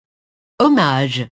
hommage [ɔmaʒ]